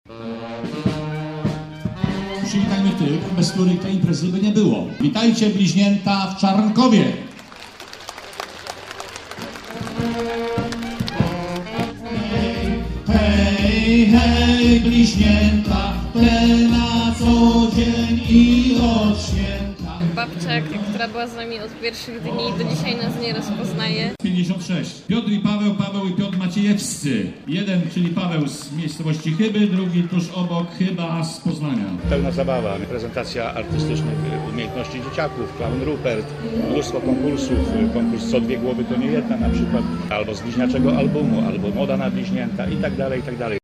Byliśmy tam dziś z radiowymi koziołkami i cykliczną audycją Radio Na Twoich Oczach.